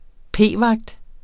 Udtale [ ˈpeˀˌvɑgd ]